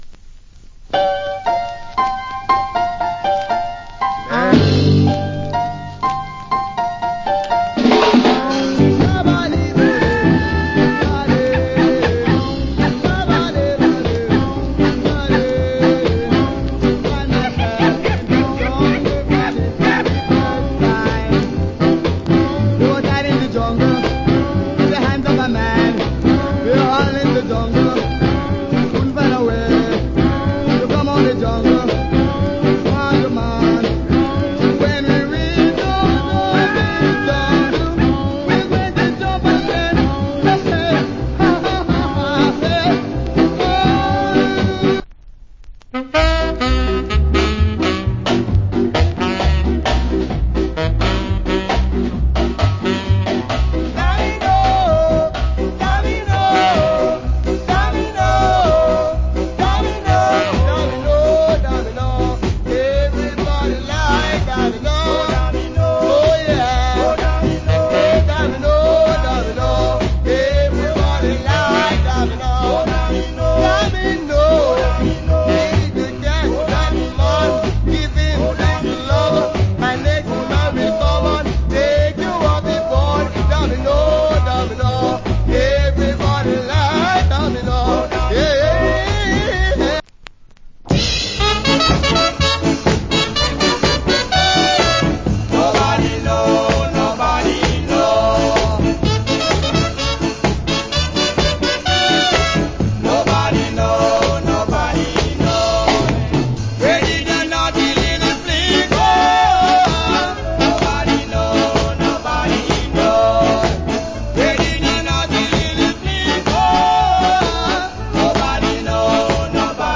Great Ska.